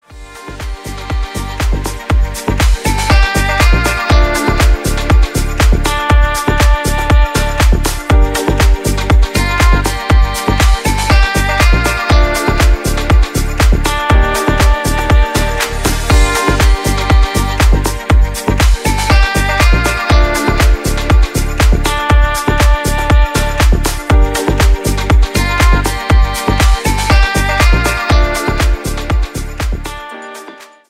• Качество: 192, Stereo
deep house
спокойные
красивая мелодия
Классный рингтон в стиле deep house.